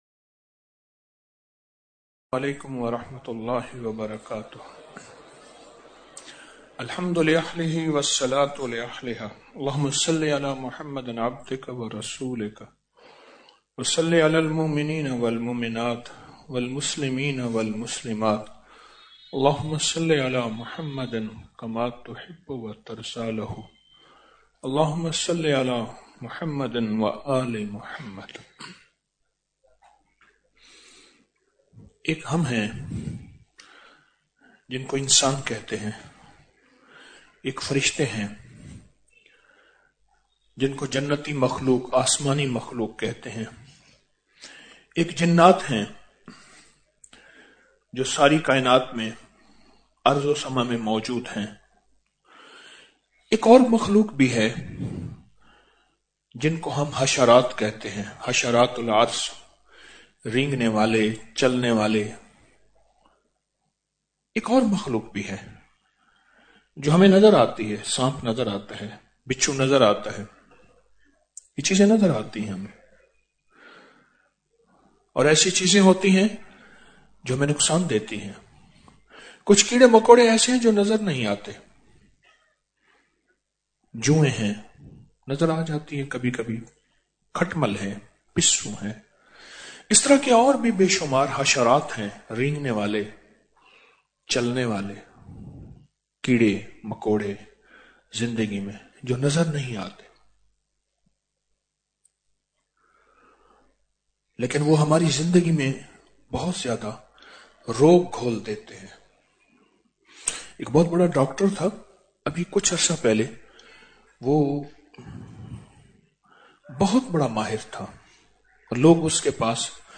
Audio Speech - Shab e Jumma Mehfil - 16 ٖJanuary 2025